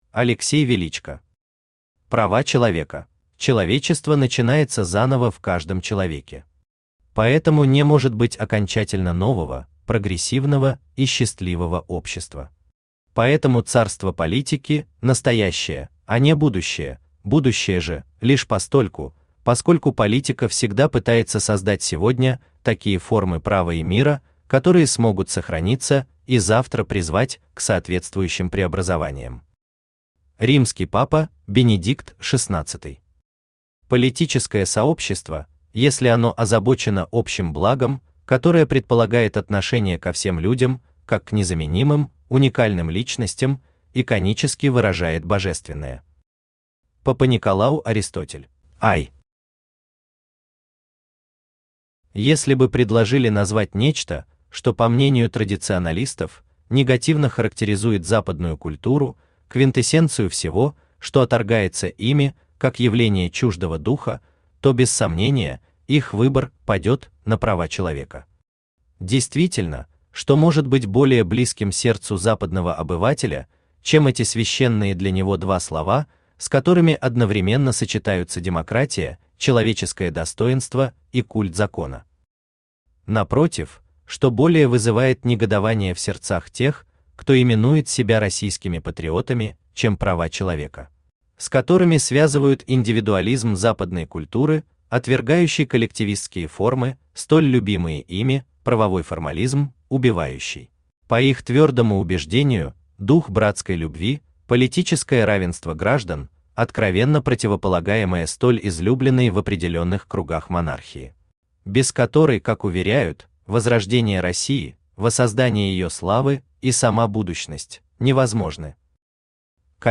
Аудиокнига Права человека | Библиотека аудиокниг
Aудиокнига Права человека Автор Алексей Михайлович Величко Читает аудиокнигу Авточтец ЛитРес.